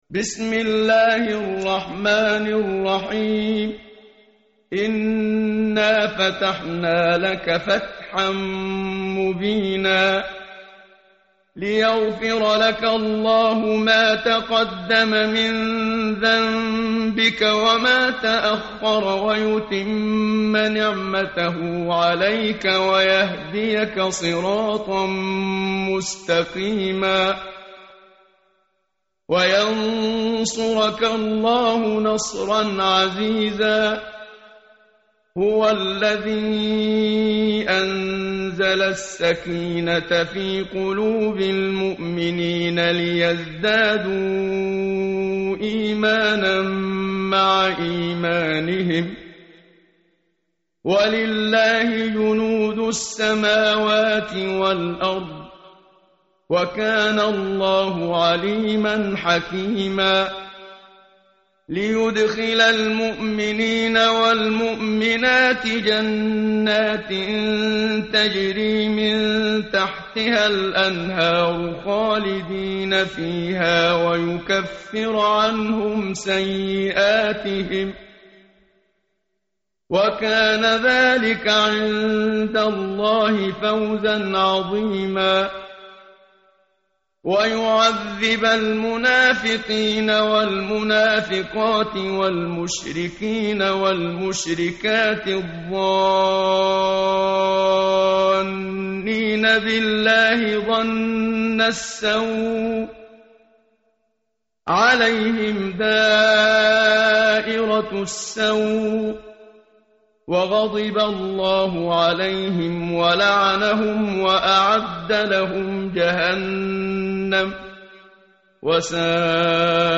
متن قرآن همراه باتلاوت قرآن و ترجمه
tartil_menshavi_page_511.mp3